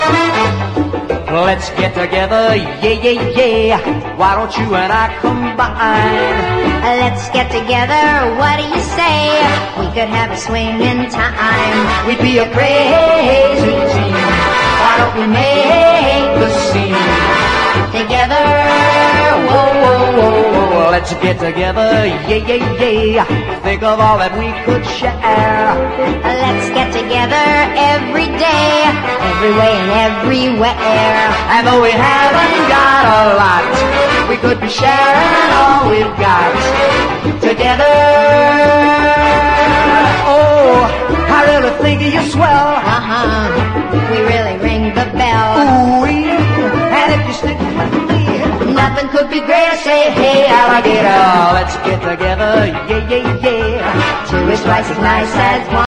EASY LISTENING / VOCAL / OLDIES (US)